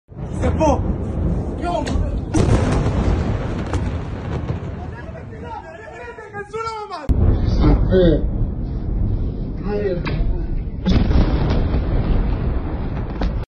انفجار مرگ بار پالایشگاه نفت sound effects free download